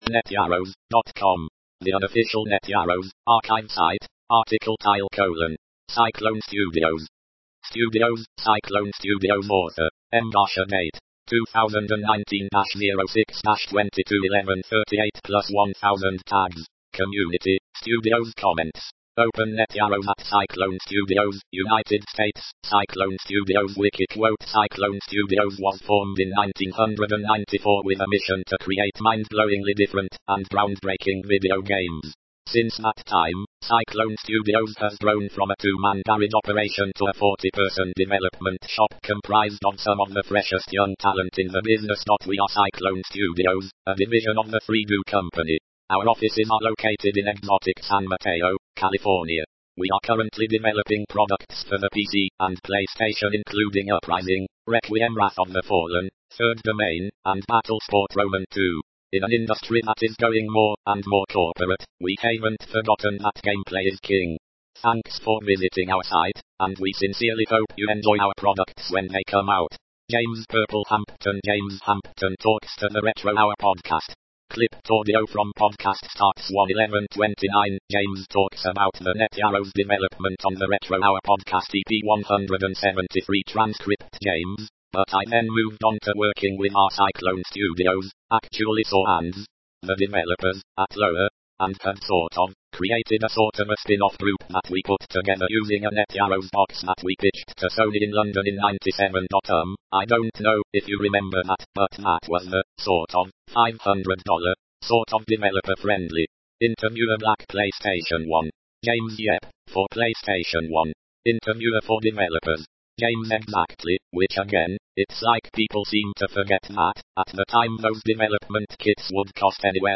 Clipped audio from podcast